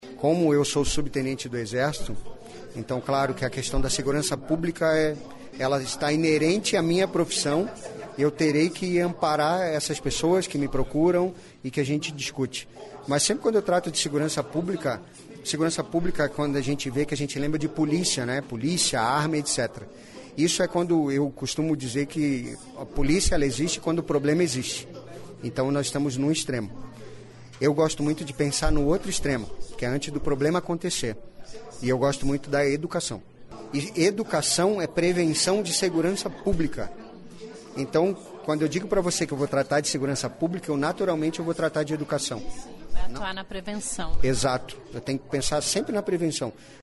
Ouça a entrevista de apresentação do parlamentar, que é do PSL.